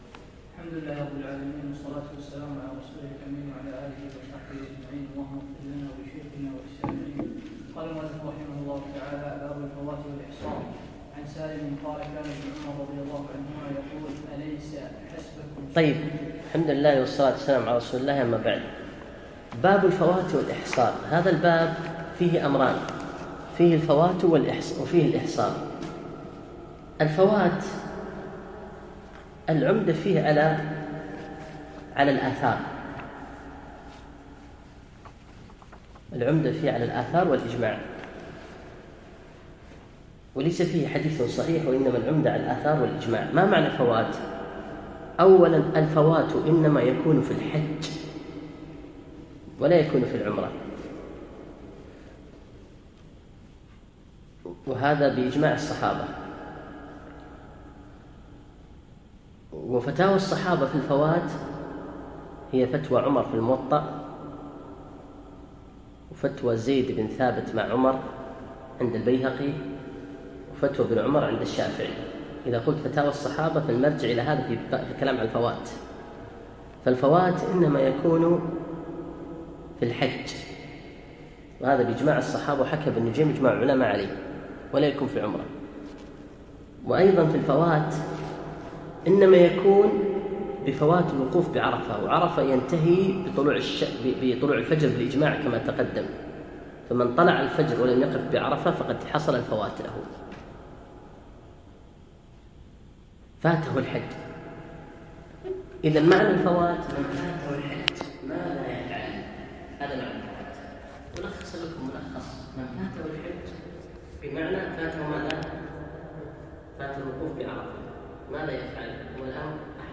يوم الاربعاء11 شوال 1438 الموافق 5 7 2017 في مسجد زين العابدين سعد العبدالله